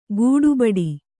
♪ gūḍu baḍi